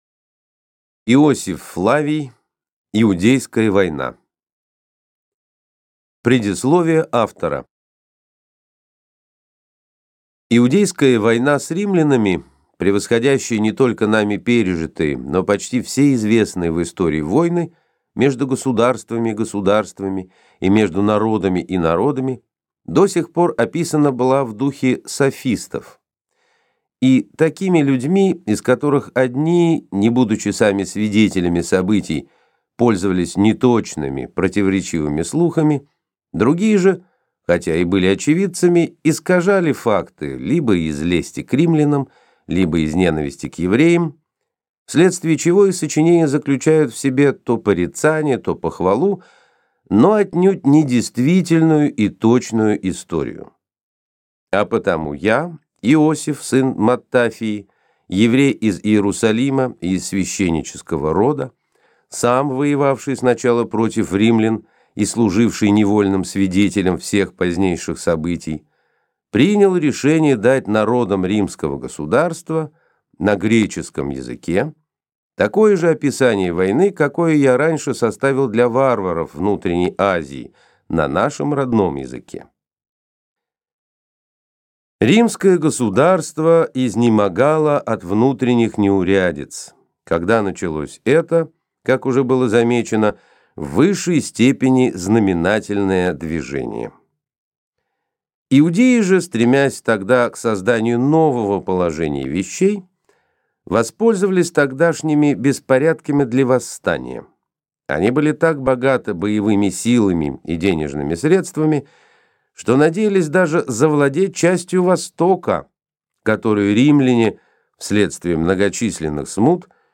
Аудиокнига Иудейская война | Библиотека аудиокниг